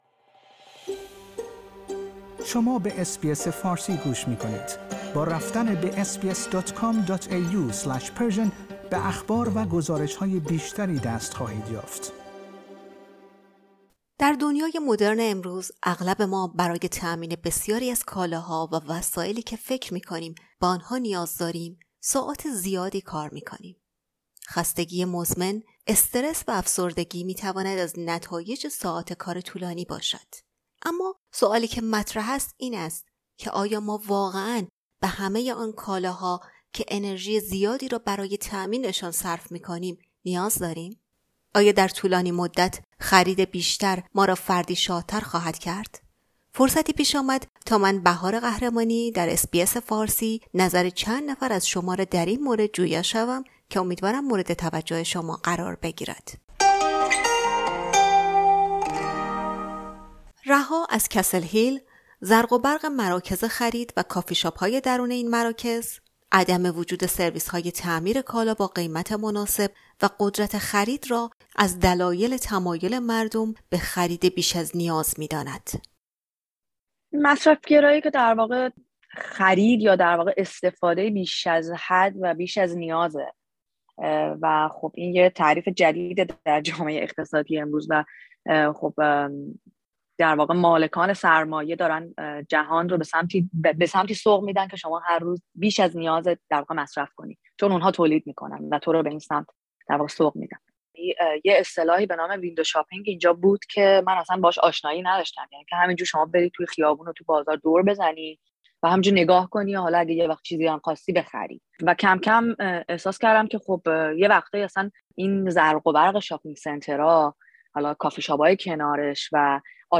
مصرفگرایی چه تاثیراتی بر شخص، جامعه، اقتصاد و محیط زیست دارد و چگونه می توان با گسترش فرهنگ مصرفگرایی مقابله کرد؟ در این گزارش نظر چند نفر را در این مورد جویا شدیم.